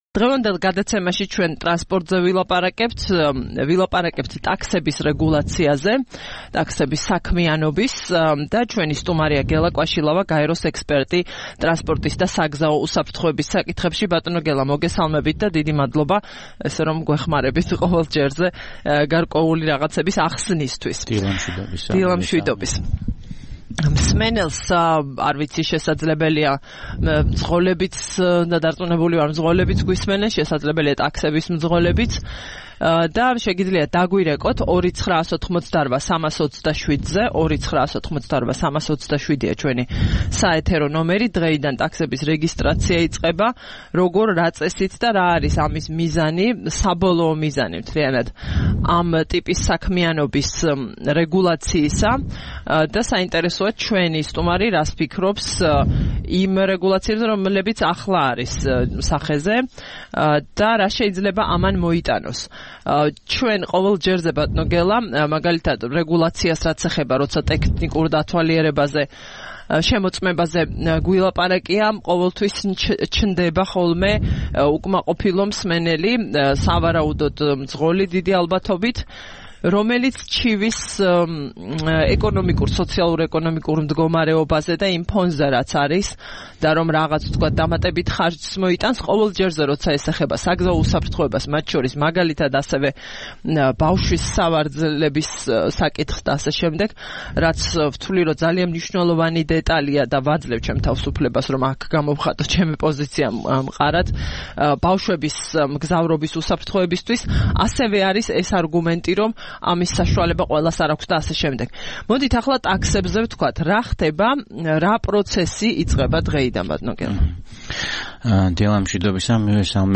1 აგვისტოს რადიო თავისუფლების "დილის საუბრების" სტუმარი იყო